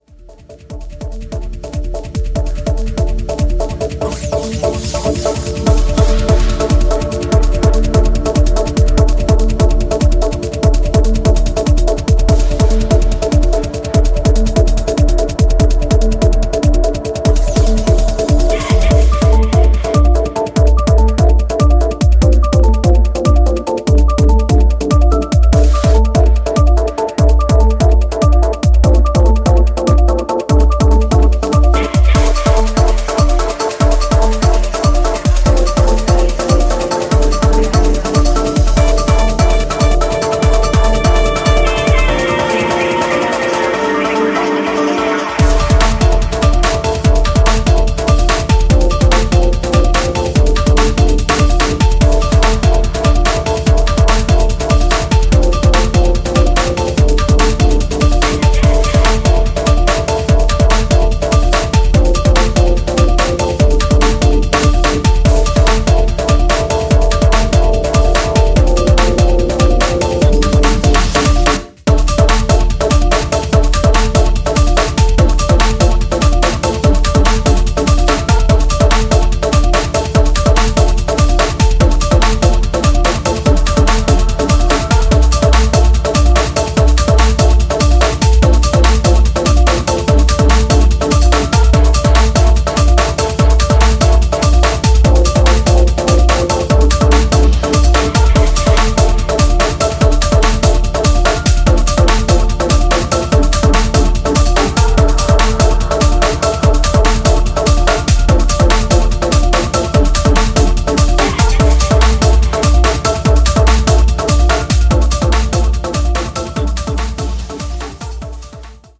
Maximal industrial techno.
Techno